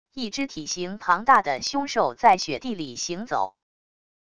一只体型庞大的凶兽在雪地里行走wav音频